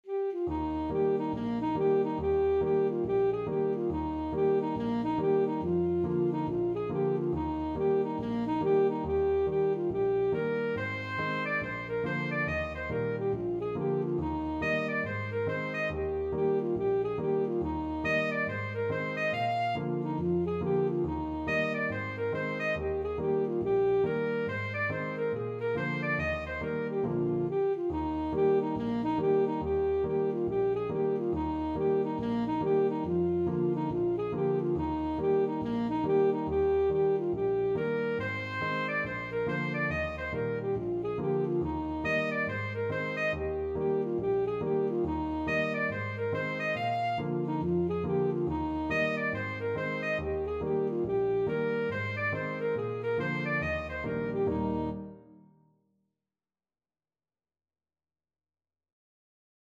Alto Saxophone
4/4 (View more 4/4 Music)
Eb major (Sounding Pitch) C major (Alto Saxophone in Eb) (View more Eb major Music for Saxophone )
Traditional (View more Traditional Saxophone Music)